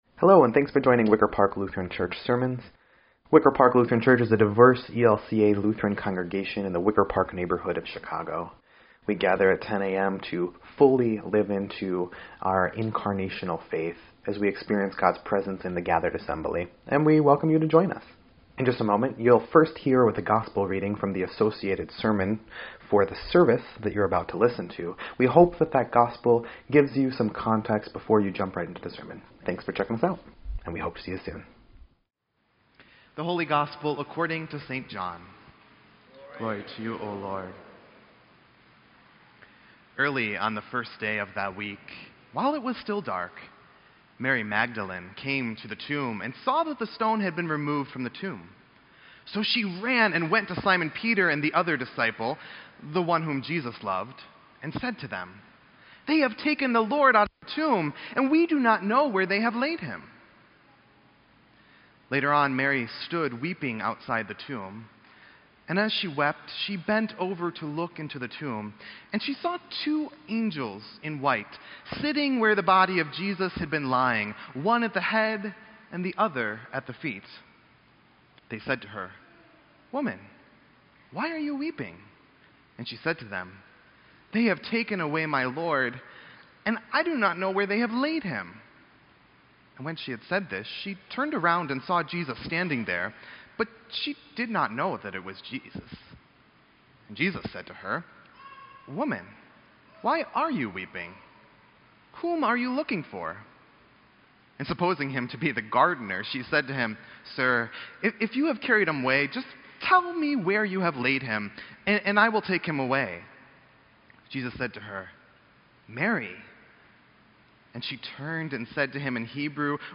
Sermon_7_22_18_EDIT.mp3